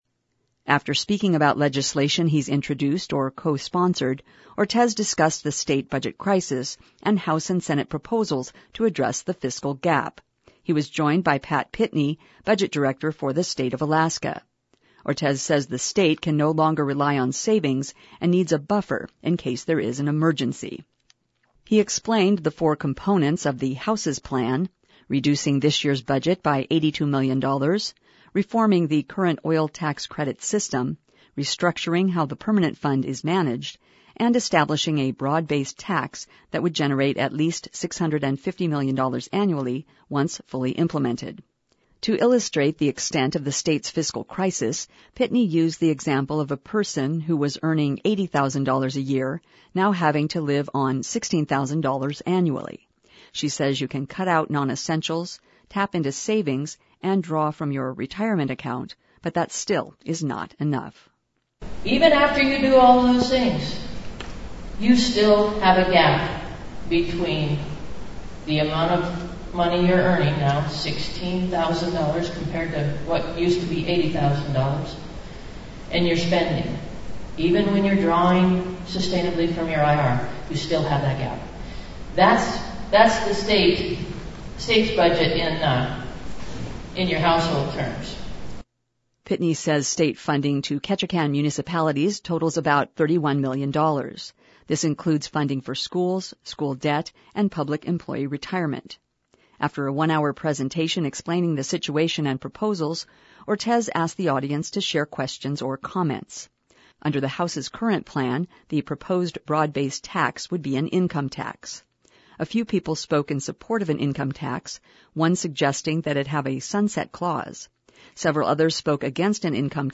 Representative Dan Ortizheld a town hall meeting in Ketchikan Thursday night.
After a one-hour presentation explaining the situation and proposals, Ortiz asked the  audience to share questions or comments.